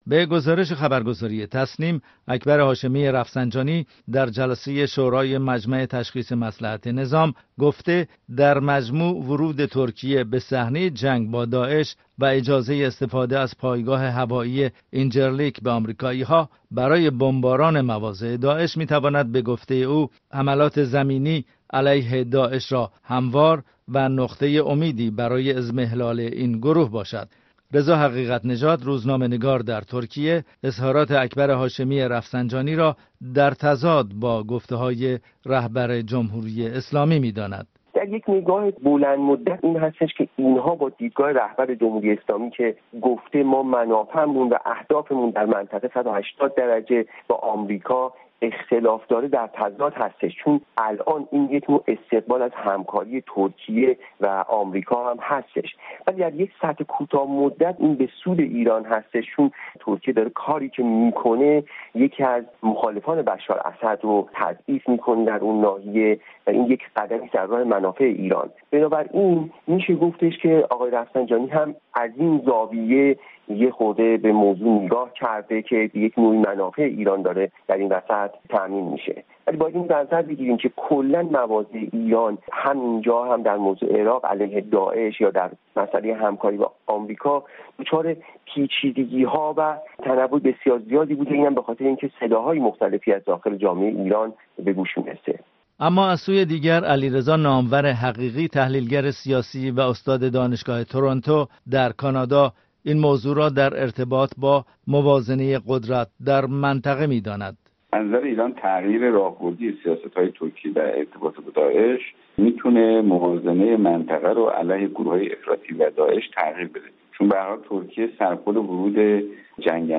در این گزارش، پرسش یاد شده را با دو کارشناس در ميان گذاشتم.